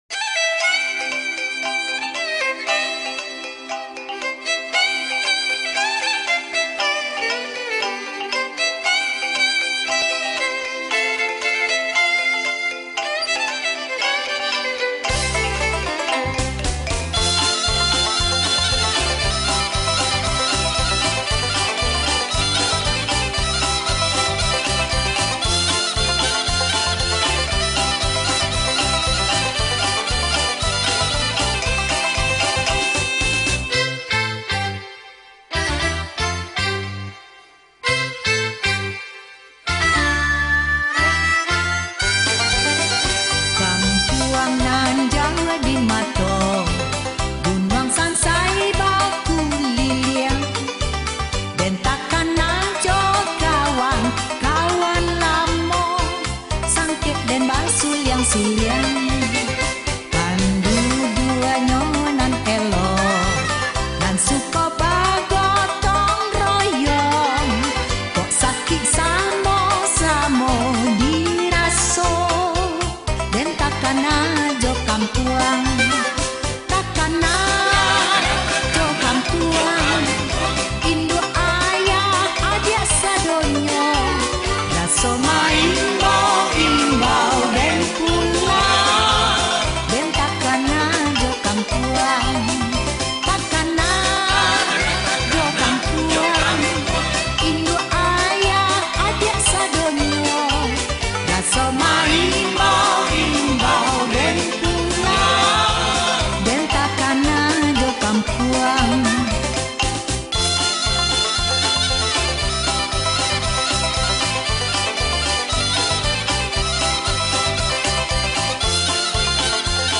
Lagu Rakyat Sumatera Barat
Nusantara Old Folk Song Rearranged By
Chords : E